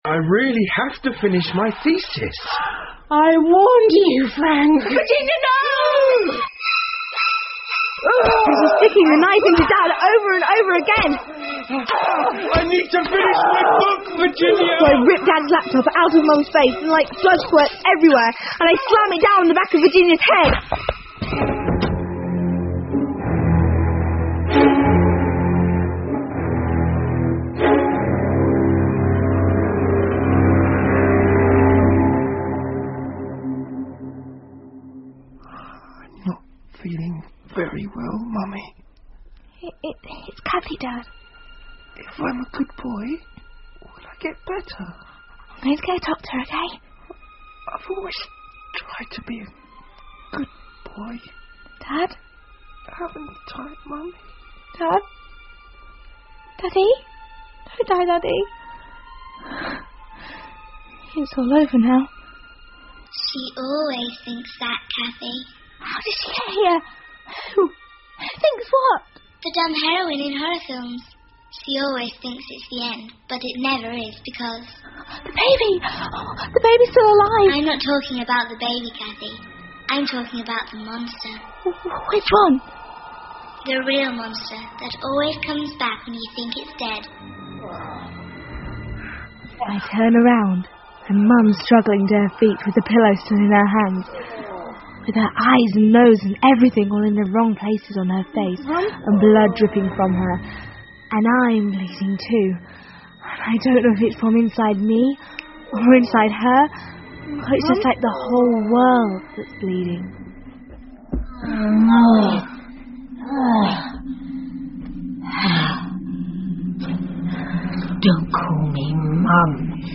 怪物妈妈 The Monstrous Mother 儿童英文广播剧 11 听力文件下载—在线英语听力室